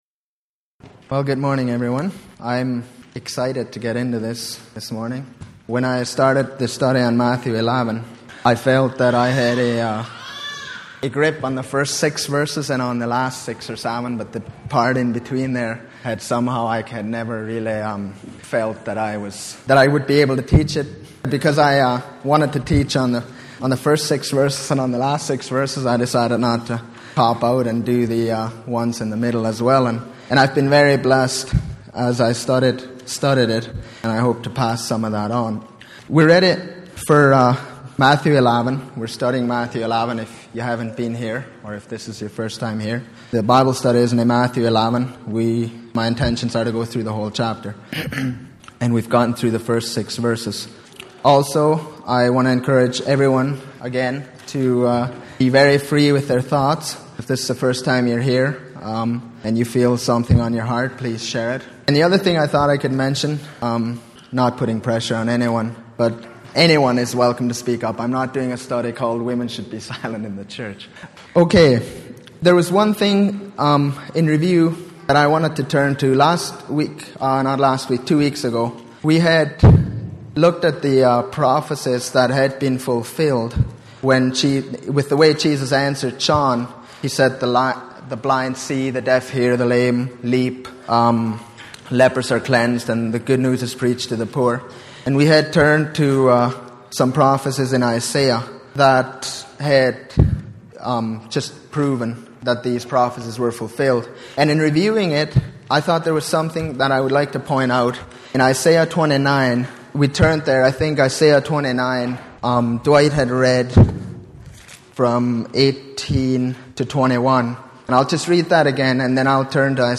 Passage: Matthew 11:7-24 Service Type: Sunday Morning